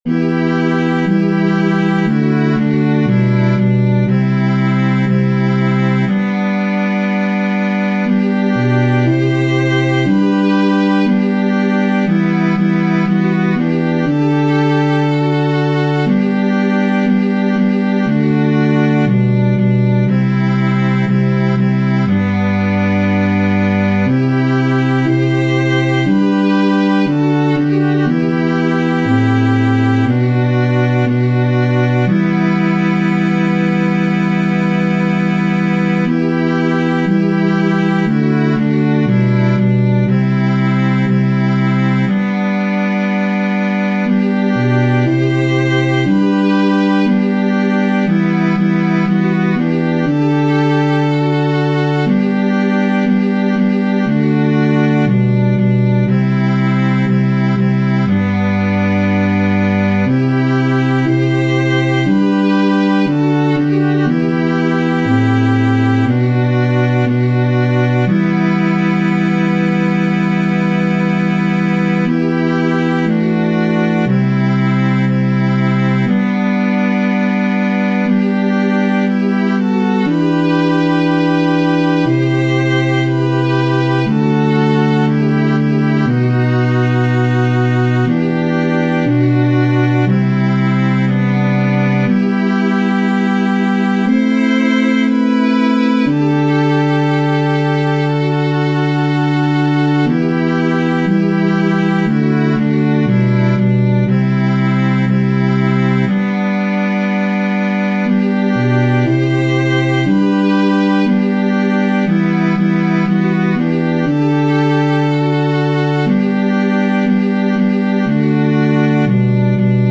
Chants de Communion Téléchargé par